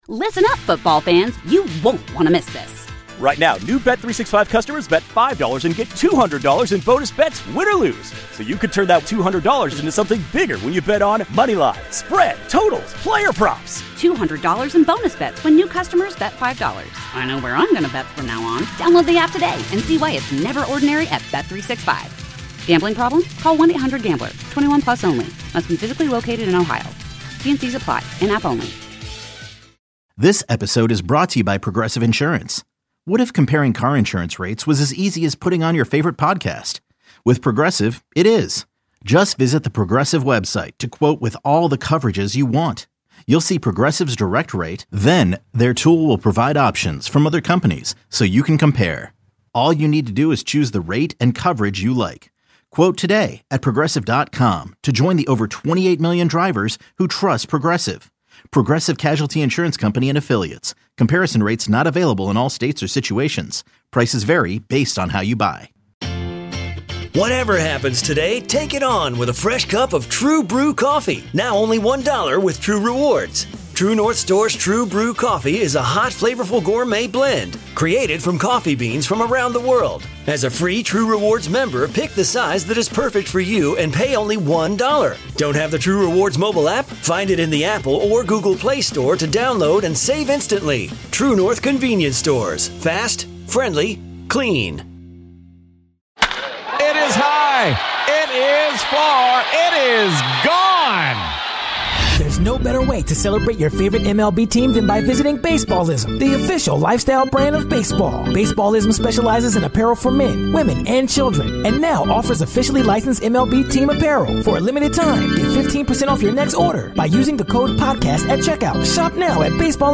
Full Interview with White Sox GM Rick Hahn | South Siders